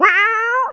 CAT.WAV